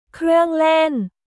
クルァンレン